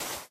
snapshot / assets / minecraft / sounds / dig / sand3.ogg
sand3.ogg